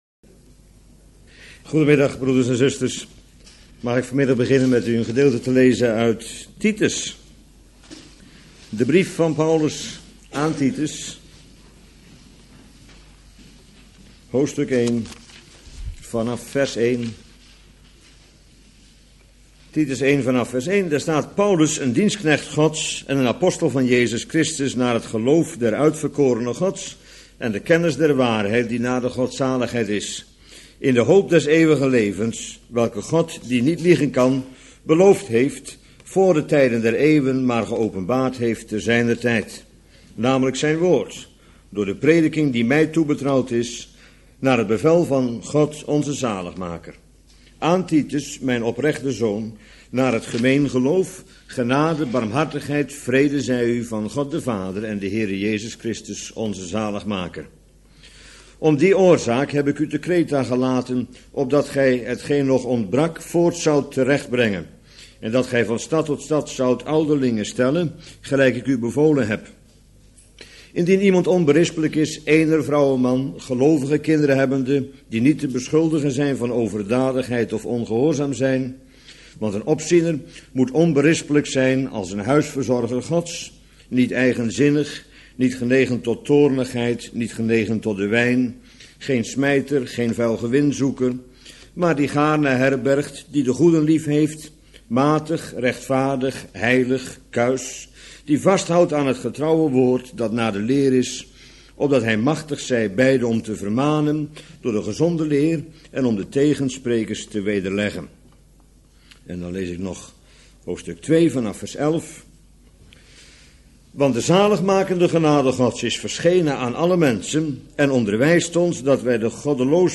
Bijbelstudie lezing